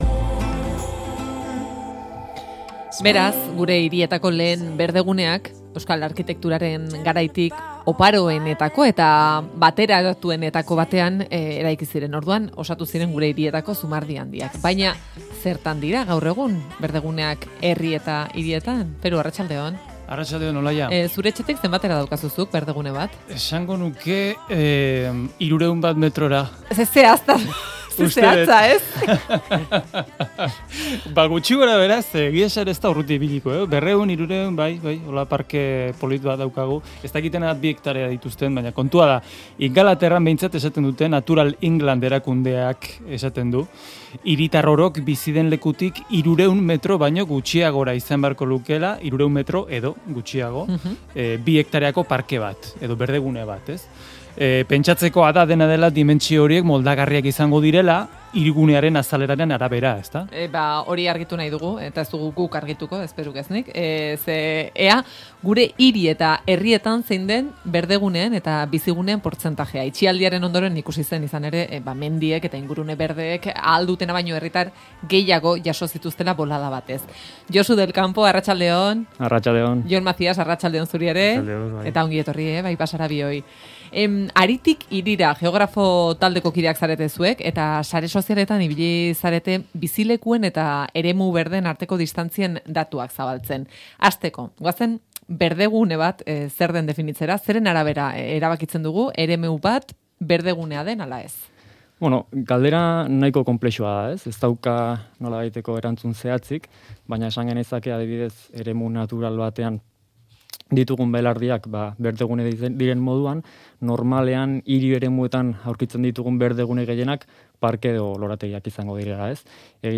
Euskal hiri eta herrietako berdeguneez aritu gara hizketan Haritik Hirira geografo taldearekin